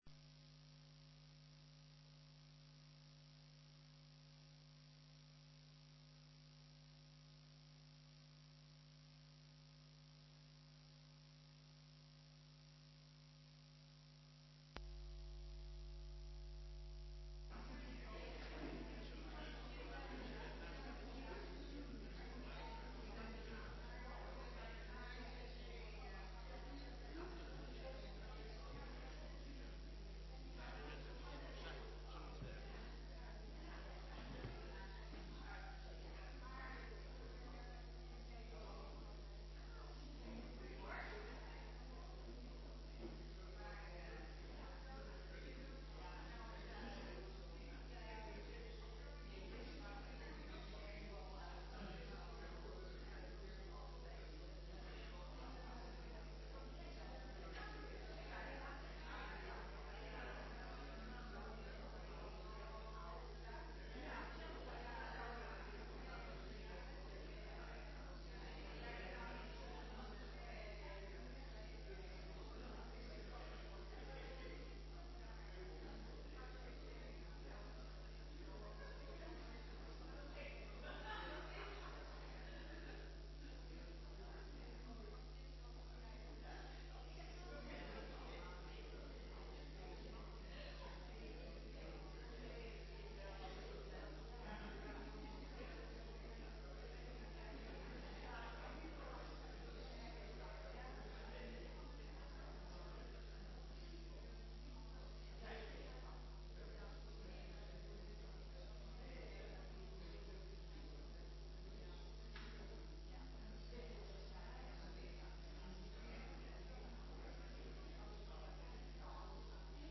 De liederen die zijn gezongen tijdens de dienst waren lied 601 en 828 en Aan Soms breekt uw licht, van Huub Osterhuis en Antoine Oomen.